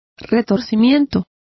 Complete with pronunciation of the translation of contortion.